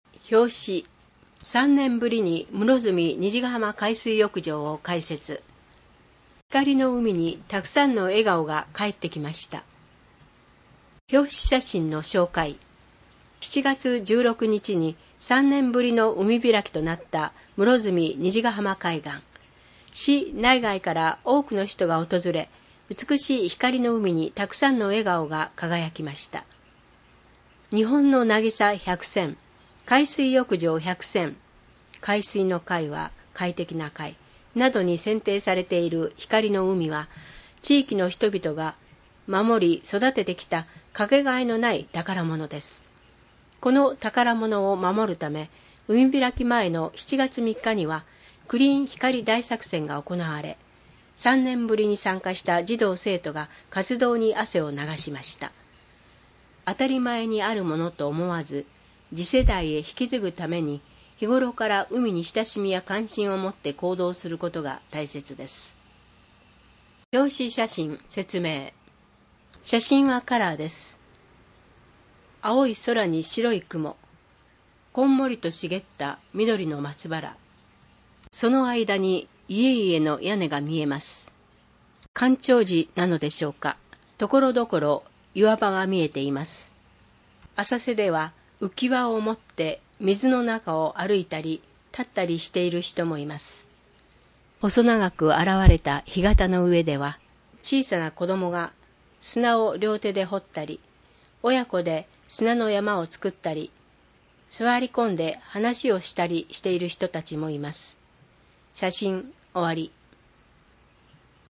広報ひかりでは、 ボランティアグループ「こだまの会」の協力により文字を読むことが困難な視覚障害者や高齢者のために広報紙の内容を音声でもお届けしています。